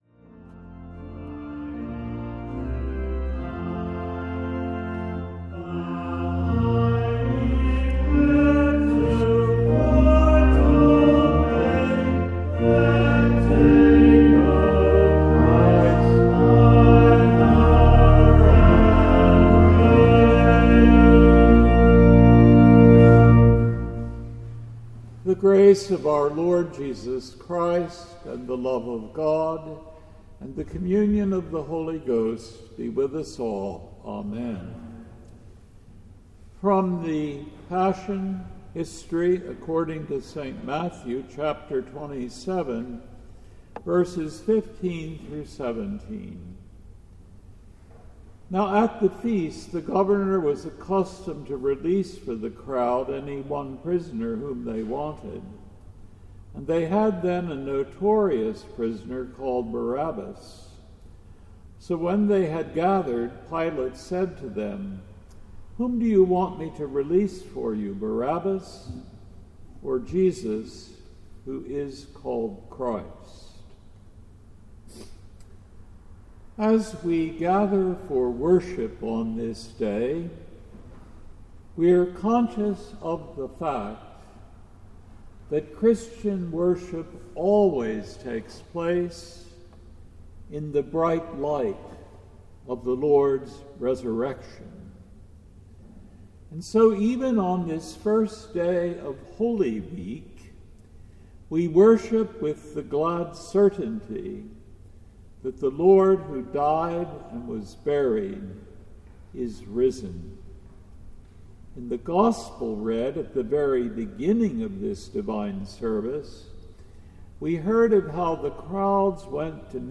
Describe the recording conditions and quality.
Palm Sunday